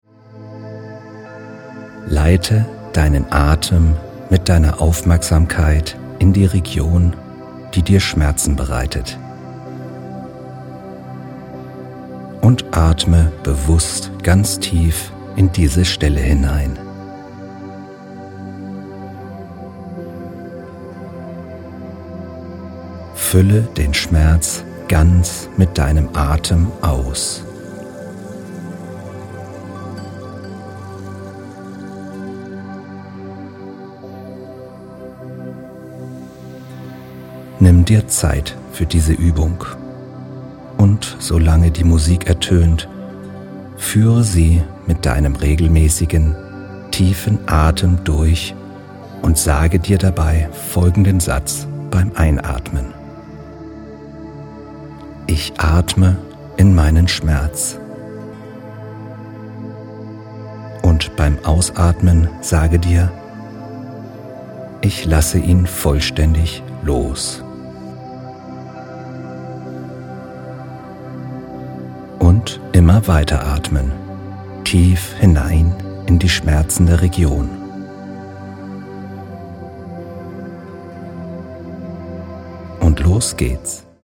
Entspannung & Atmungsstechnik & 432 Hz Musik
männliche Stimme